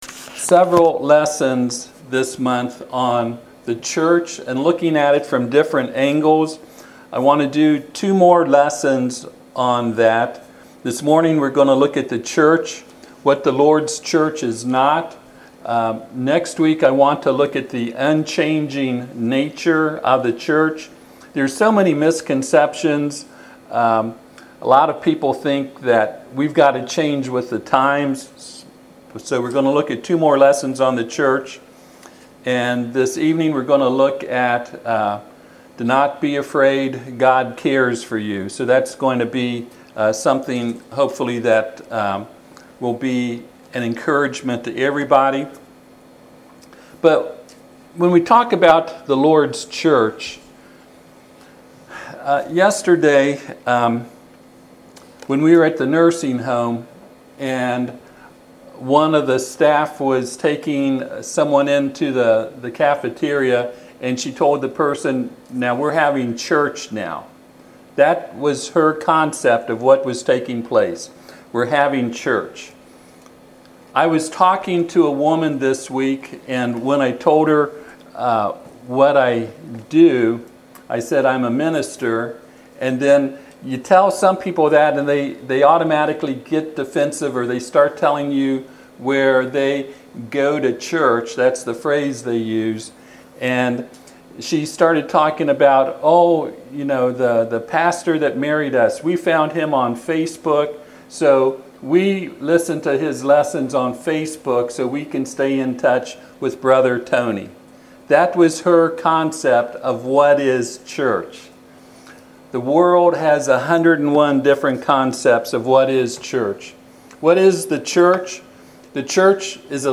Romans 14:17 Service Type: Sunday AM « Some of God’s people in the wrong place with the wrong attitude.